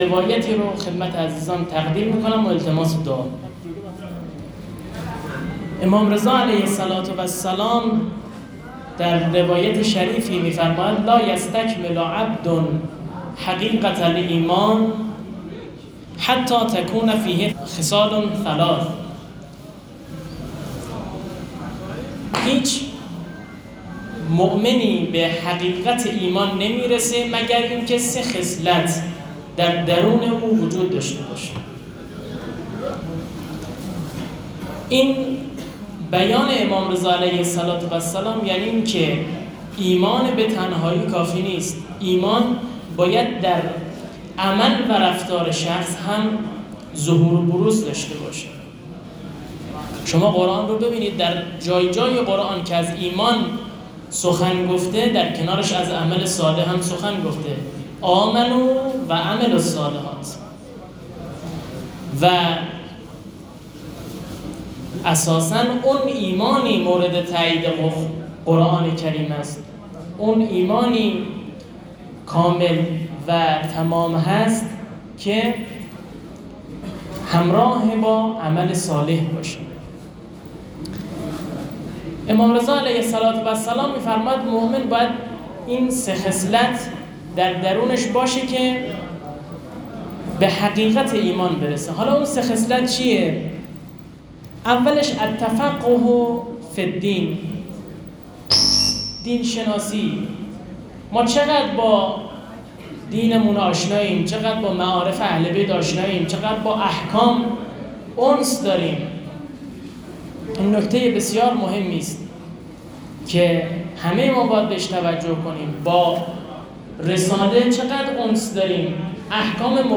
سخنرانی-میلاد-امام-رضا-علیه-السلام.mp3